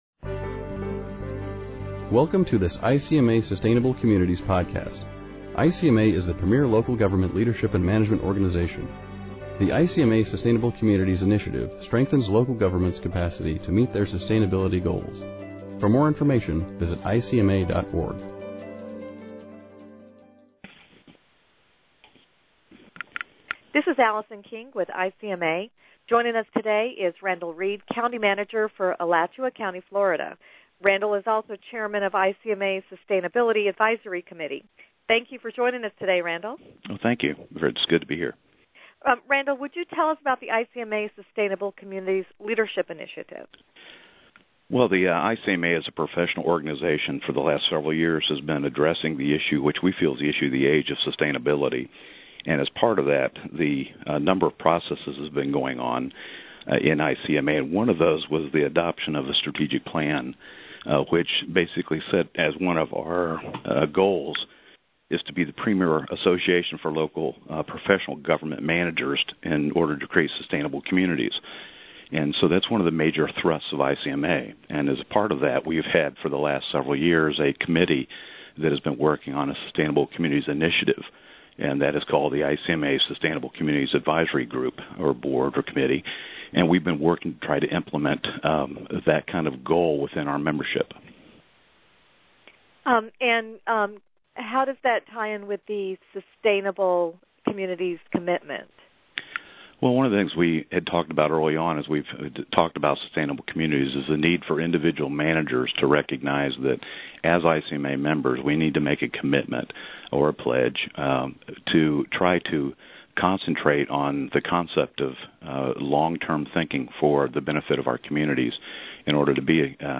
Podcast: Sustainability-A discussion with Randall Reid, chair of the ICMA sustainability advisory committee